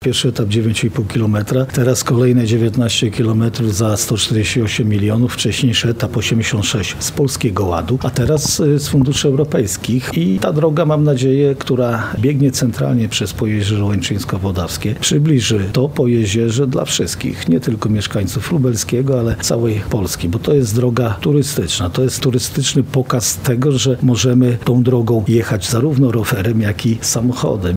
– To zwieńczenie dzieła, bo ta droga jest przebudowywana od kilku lat – mówi marszałek województwa lubelskiego, Jarosław Stawiarski.